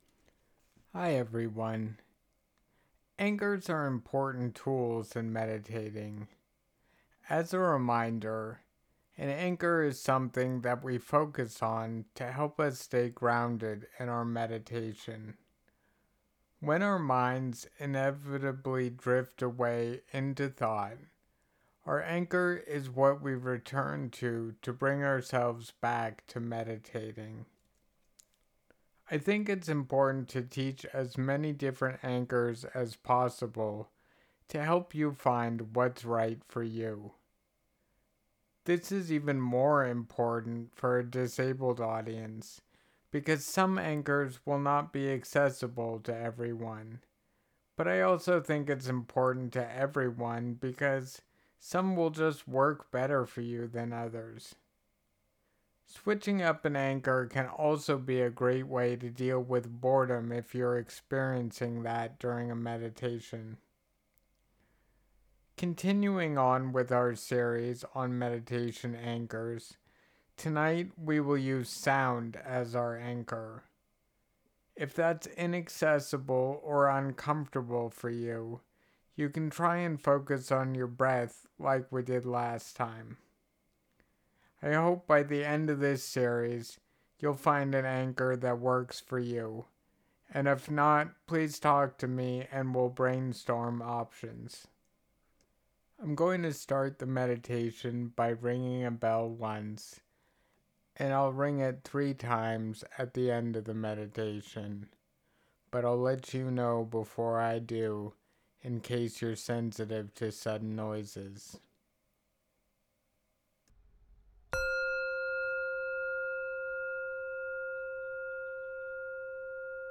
Continuing with our series on anchors, this meditation focus on sound.